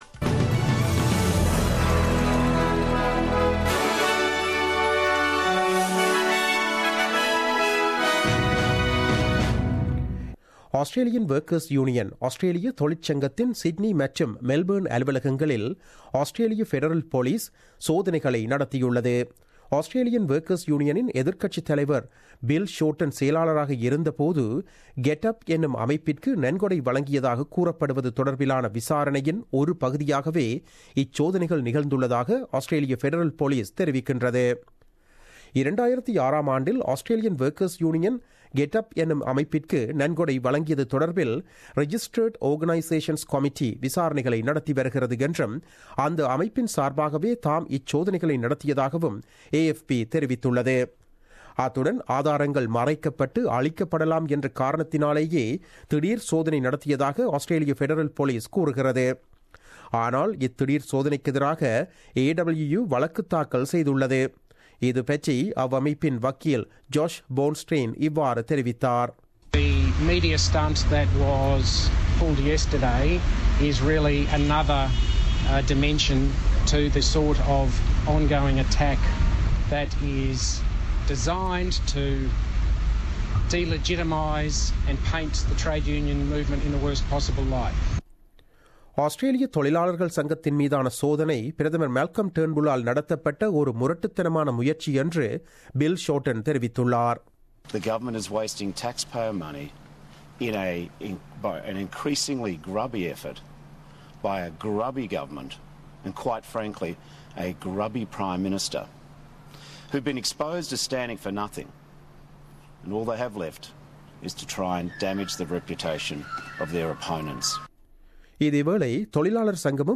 The news bulletin broadcasted on 25 October 2017 at 8pm.